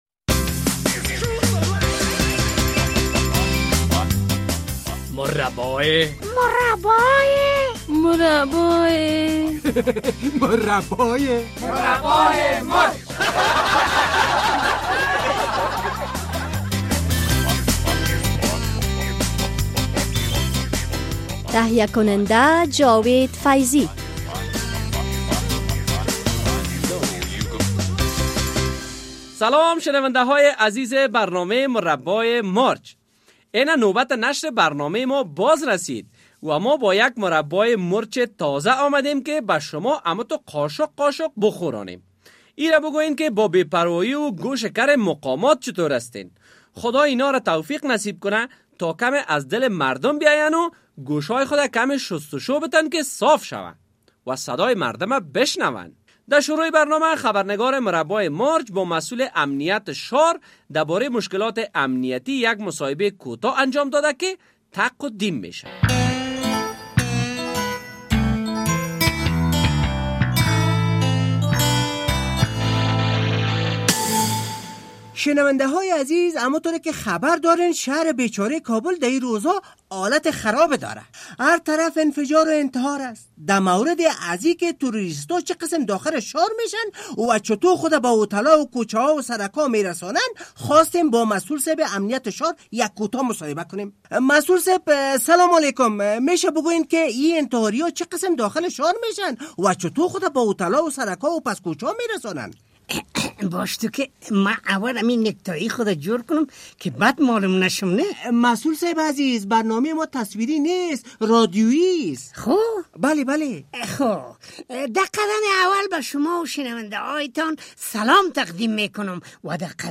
مسؤل امنیت شار و فالبین کف شناس، این هفته در مربای مرچ مصاحبه داریم با مسؤل امنیت شار ده مورد ...